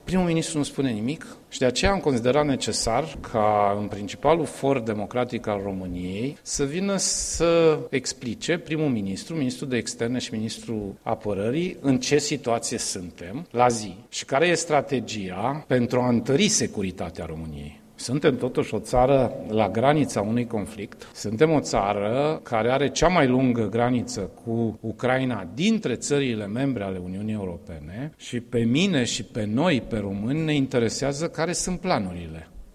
Preşedintele interimar al PSD, Sorin Grindeanu, a declarat astăzi la Gorj că îl aşteaptă pe premierul Ilie Bolojan să vină în Parlament pentru a prezenta implicaţiile asupra angajamentelor strategice şi de securitate ale României: